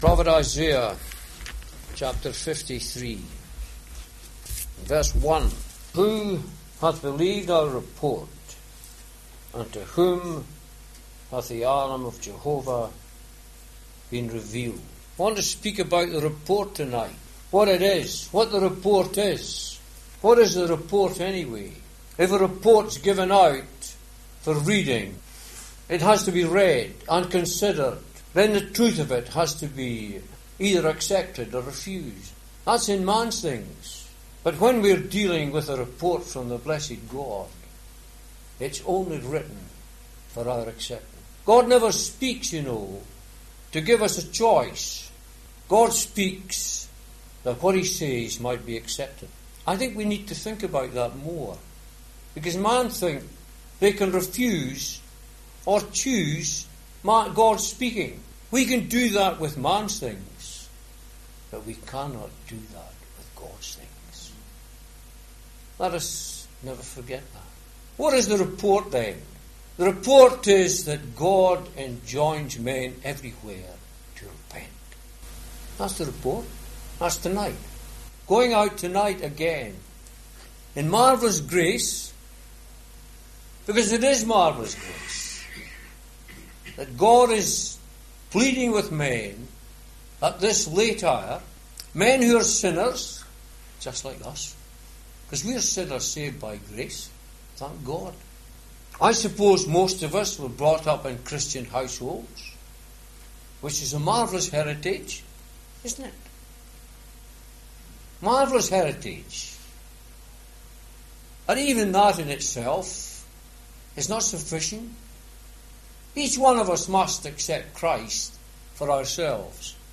In this Gospel preaching, you will hear of a report from the blessed God.